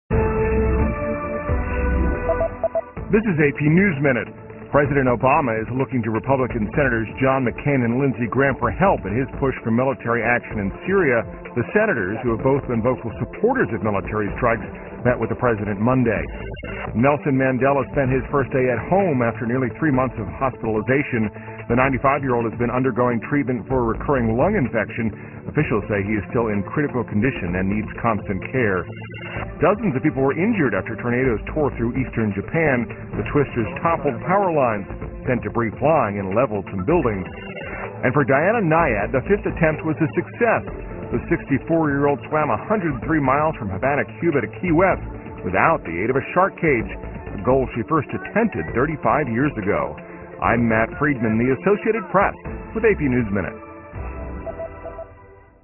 在线英语听力室美联社新闻一分钟 AP 2013-09-05的听力文件下载,美联社新闻一分钟2013,英语听力,英语新闻,英语MP3 由美联社编辑的一分钟国际电视新闻，报道每天发生的重大国际事件。电视新闻片长一分钟，一般包括五个小段，简明扼要，语言规范，便于大家快速了解世界大事。